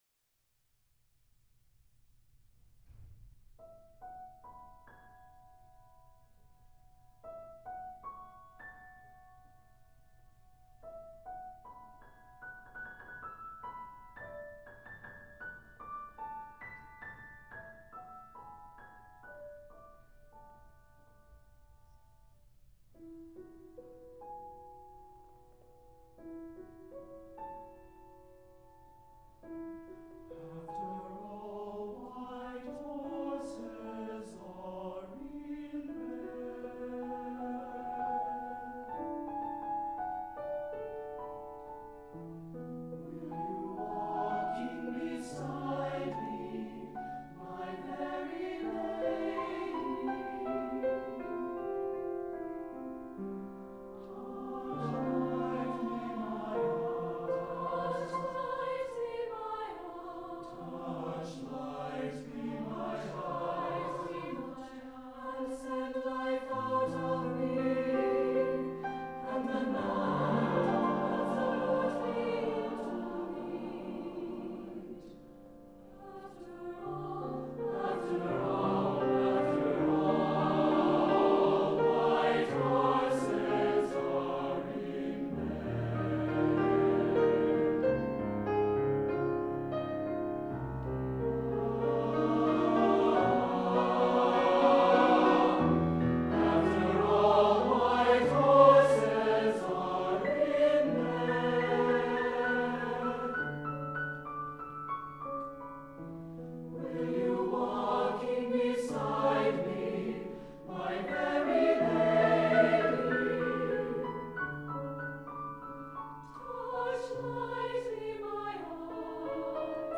for SATB Chorus and Piano (1979)
This choral work may be interpreted as a love song hearkening back to the days of the Troubadours. Thus, the piano introduction (in high range) might function to transport the listener away from present day reality and into night and the past.